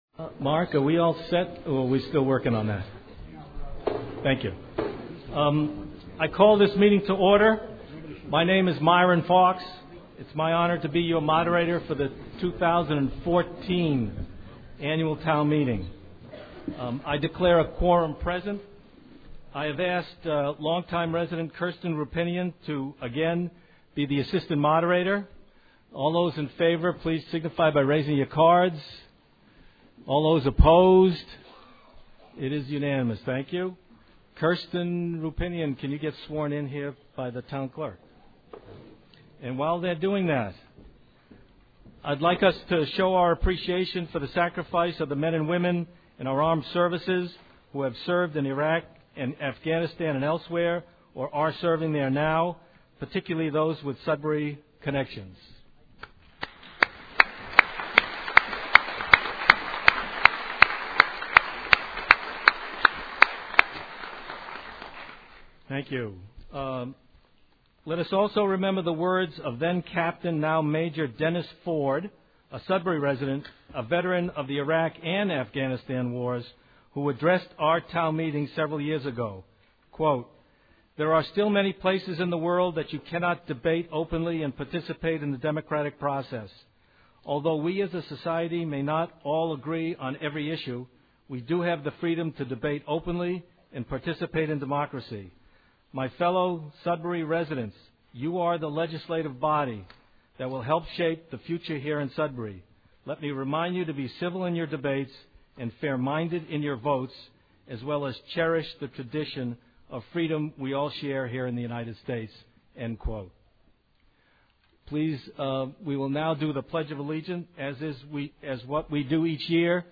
Town Meeting 2014, May 05